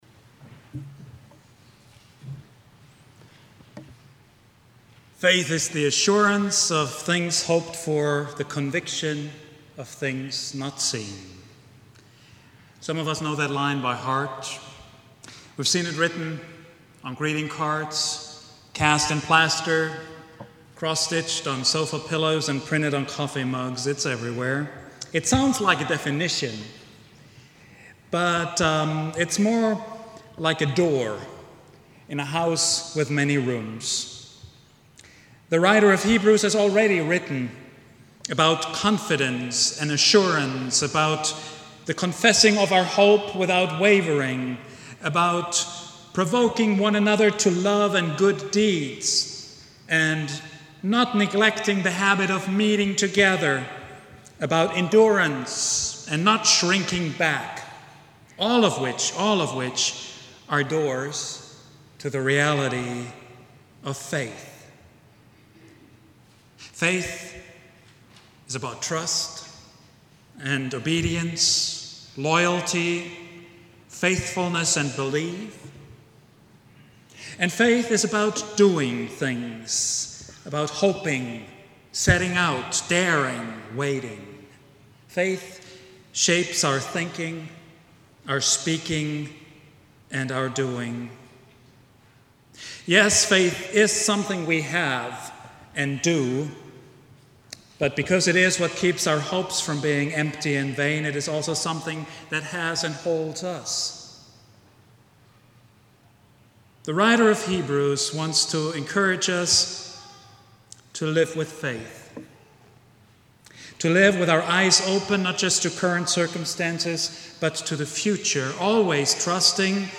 sermon
preached at Vine Street Christian Church on Sunday, August 11, 2013.